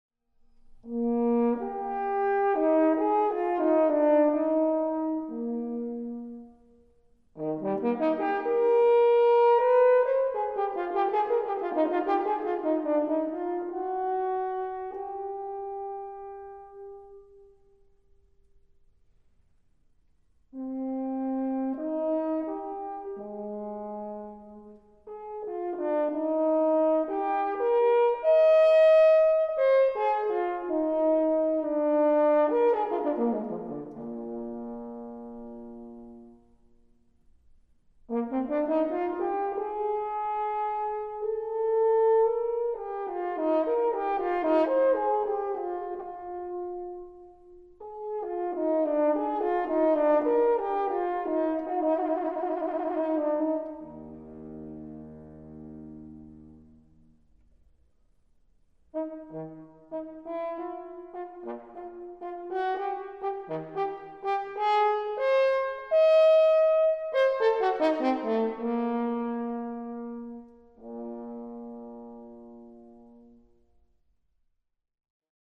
French Horn